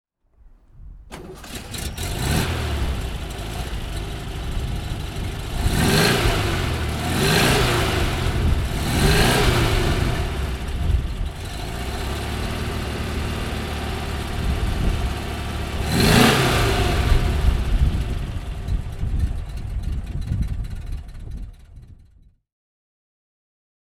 Porsche 356 A 1600 Super (1957) - Starten und Leerlauf